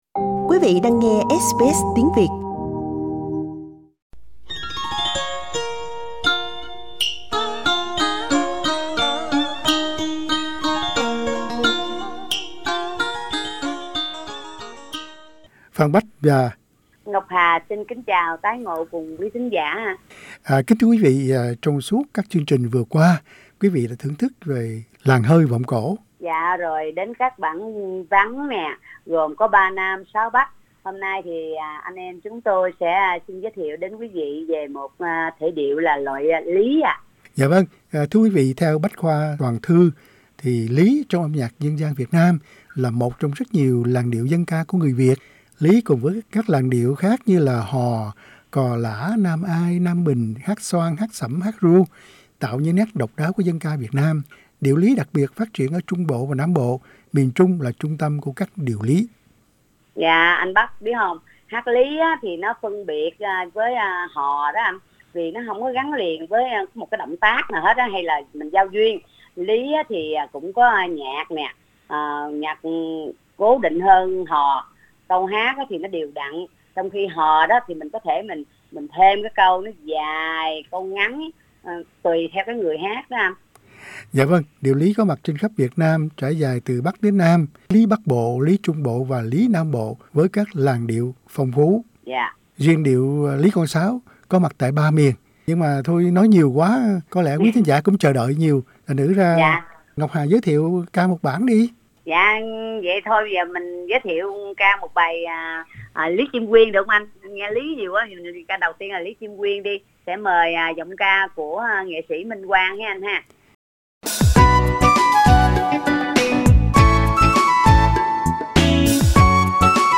Nhóm ca cổ Ngọc Hà Source: Supplied